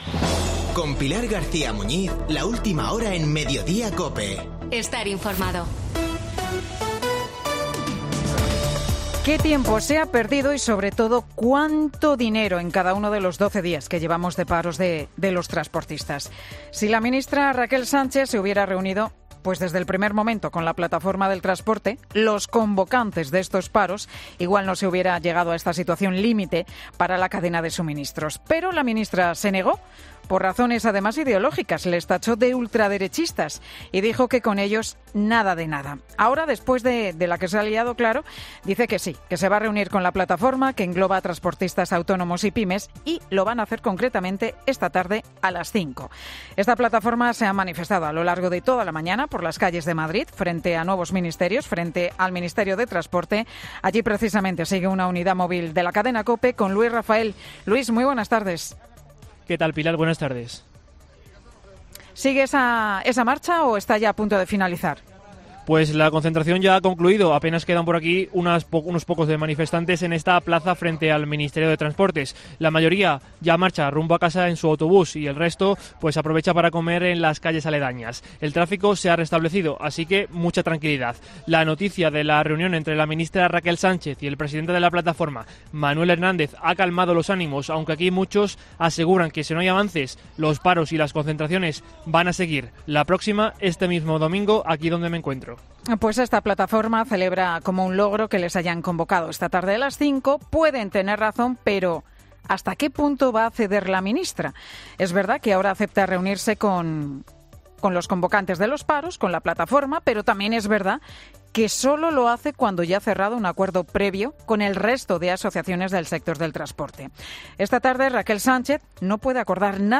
AUDIO: El monólogo de Pilar García Muñiz, en Mediodía COPE